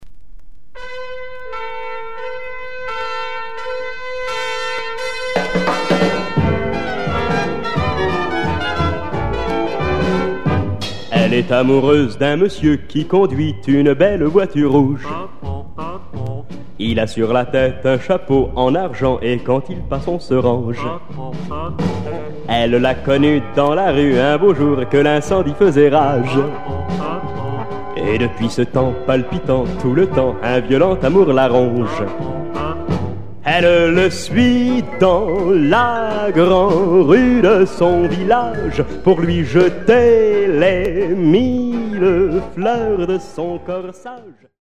& son orchestre